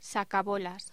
Locución: Sacabolas
voz
Sonidos: Voz humana